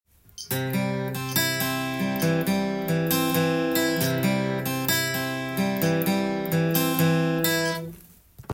ピックと指弾き強化【ギターで16分音符のアルペジオ練習】
コードはCでアルペジオパターンを譜面にしてみました。
「タタータ」というリズムで弾いていきます。